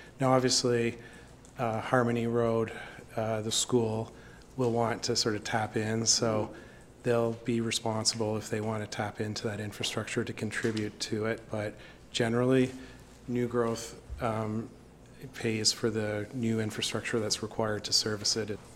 The City of Belleville’s Planning Advisory Committee hosted a public meeting Monday on the major development planned for the Black Bear Ridge Golf Course property.